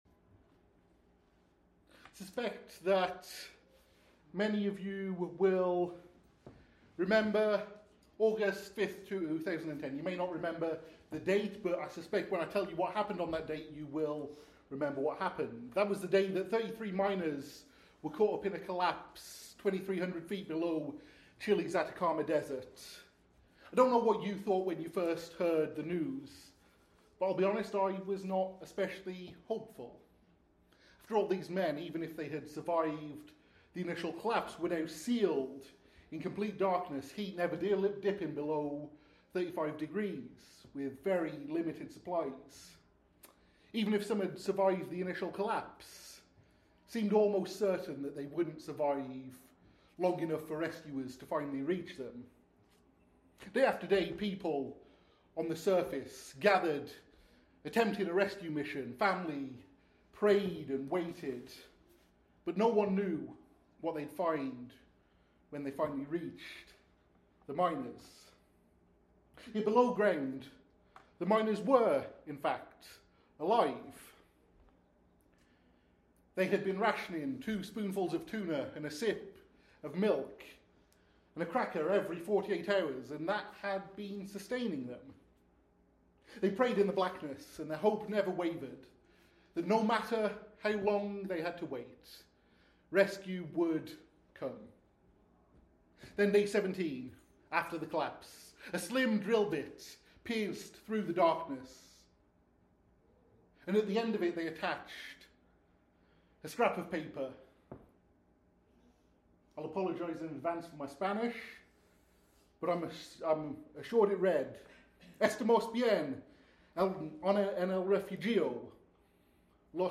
In this opening sermon of our Advent series “The Long-Awaited King,” we explore how the Old Testament prophets sustained hope through centuries of waiting—and what their promises mean for our darkness today.